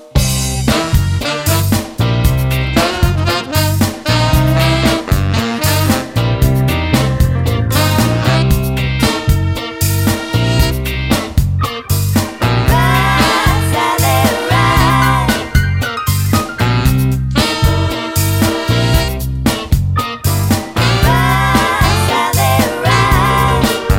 Medley - Cut Down Soundtracks 3:54 Buy £1.50